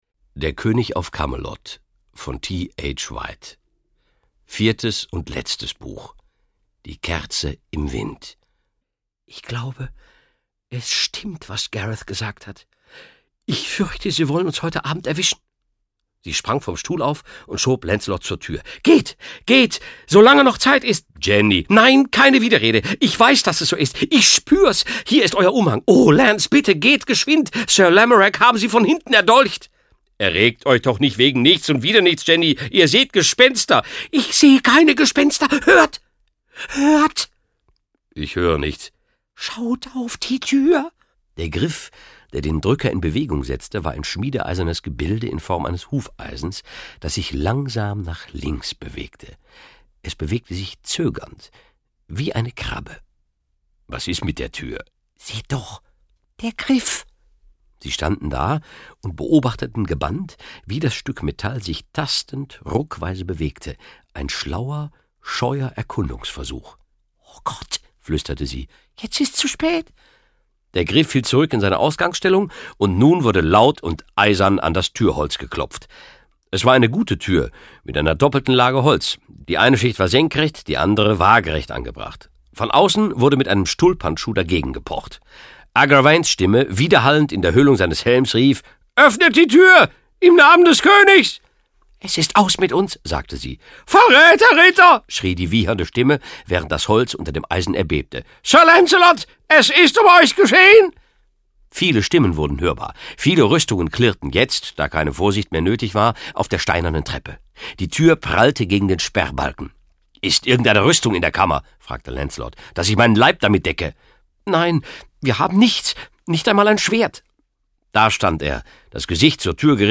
Jochen Malmsheimer (Sprecher)